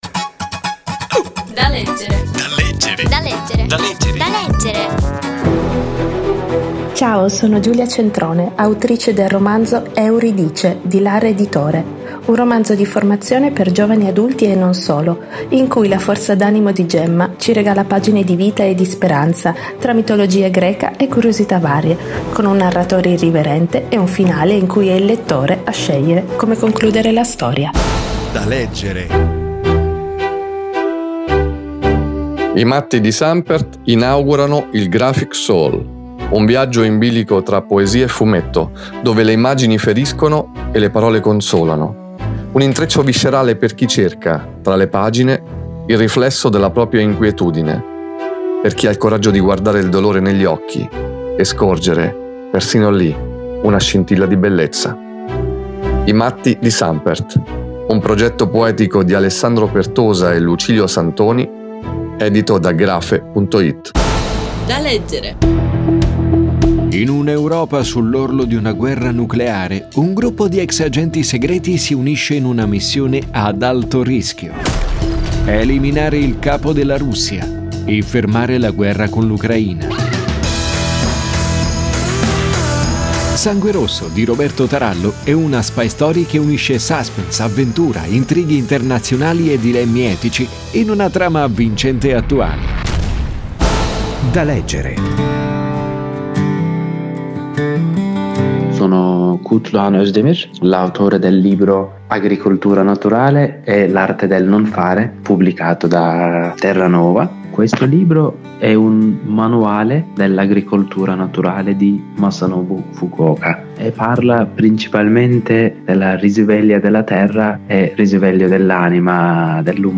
Recensione di: I matti di Sànpert
Tra i libri suggeriti dalla rubrica radiofonica ci sono anche “I matti di Sànpert”